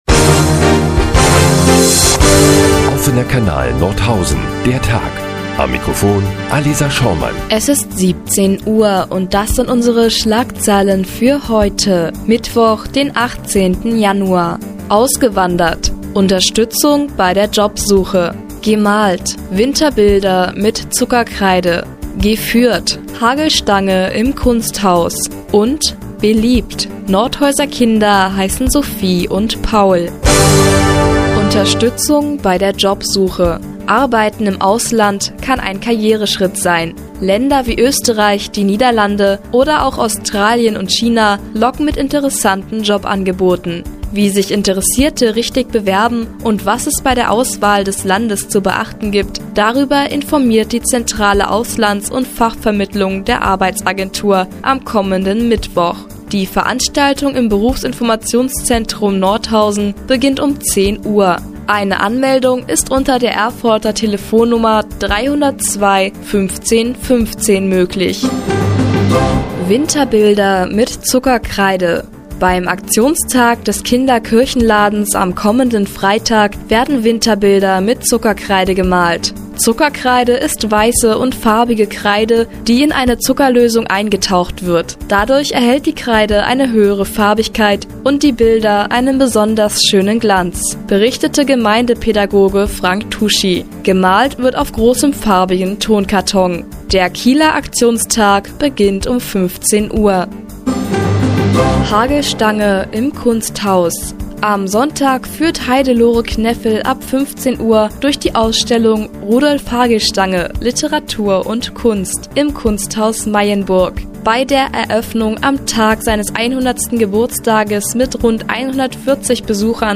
18.01.2012, 17:00 Uhr : Seit Jahren kooperieren die nnz und der Offene Kanal Nordhausen. Die tägliche Nachrichtensendung des OKN ist nun auch in der nnz zu hören.